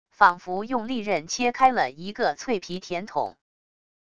仿佛用利刃切开了一个脆皮甜筒wav音频